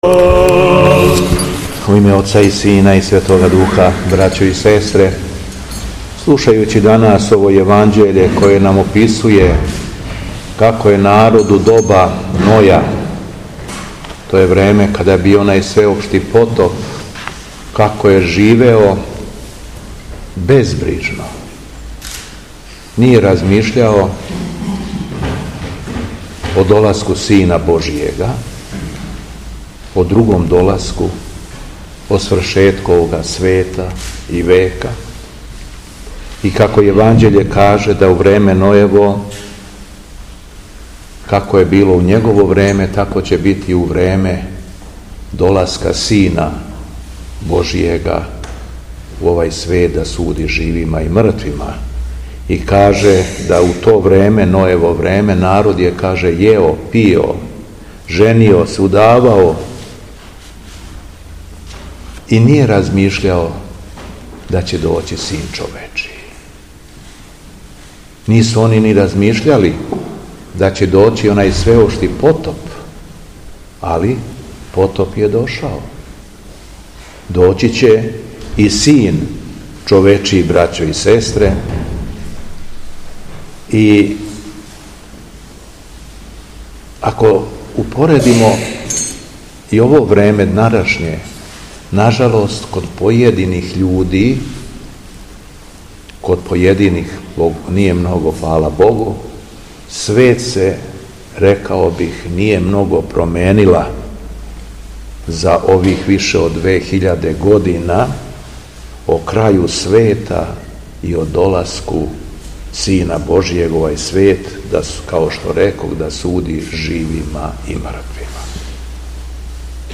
СВЕТА БОЖАНСТВЕНА АРХИЈЕРЕЈСКА ЛИТУРГИЈА У СТАНОВУ - Епархија Шумадијска
Беседа Његовог Високопреосвештенства Митрополита шумадијског г. Јована
Након прочитане јеванђељске перикопе верном лаосу се надахнутом беседом обратио Митрополит Јован: